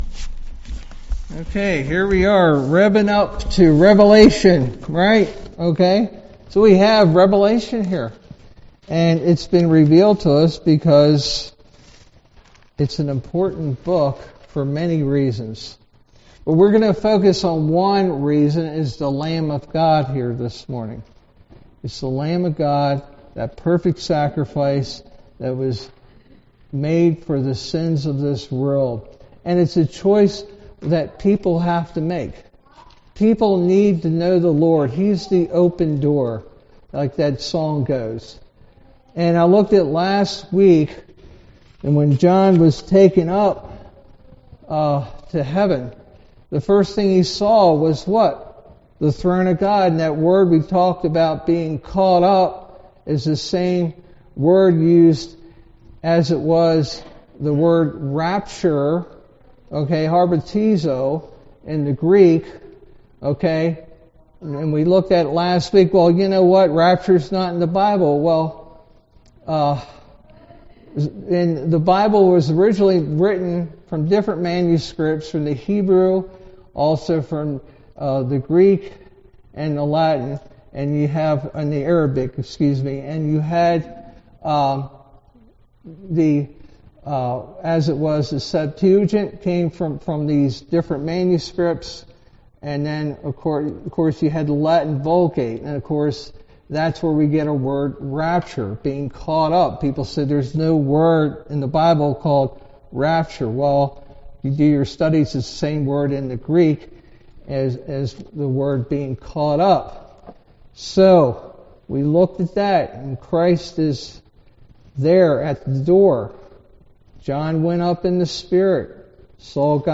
All Sermons A Vision of The Lamb 8 March 2026 Series